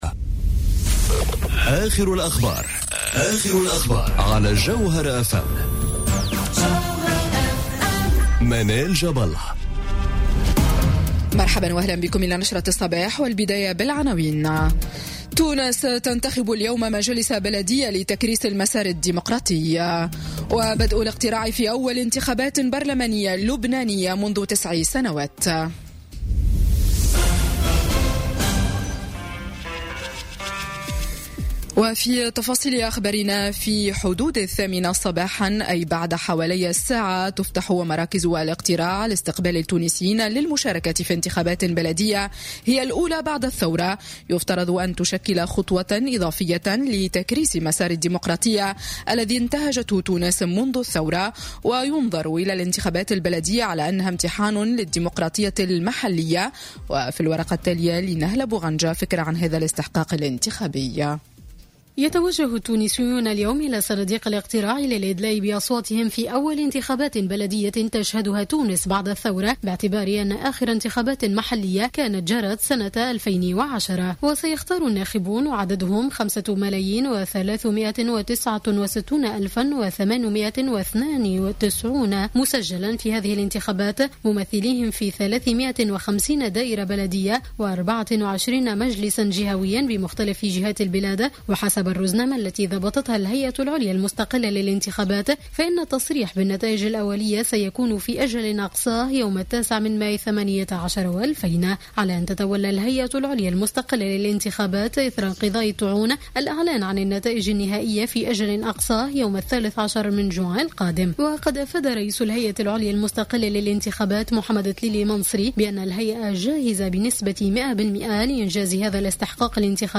نشرة أخبار السابعة صباحا ليوم الأحد 6 ماي 2018